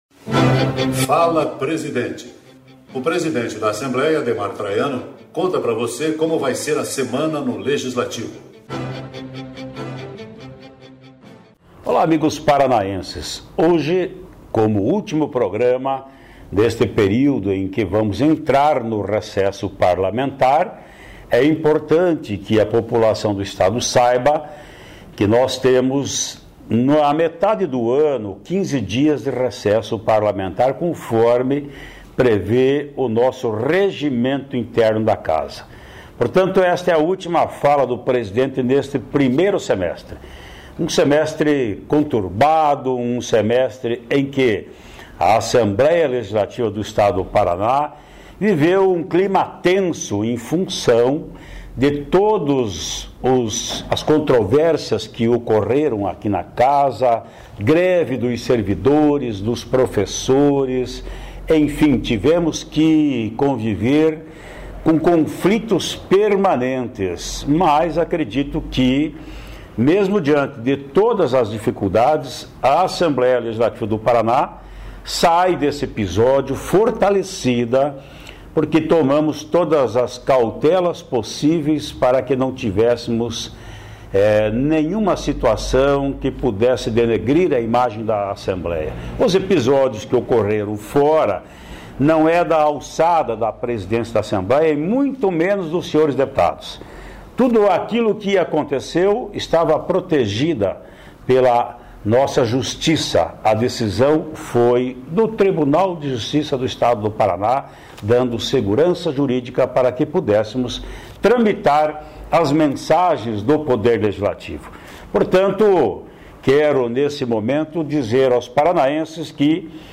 No "Fala Presidente" desta segunda-feira, (13), o deputado Ademar Traiano faz um balanço das ações da Assembleia durante o primeiro semestre de 2015.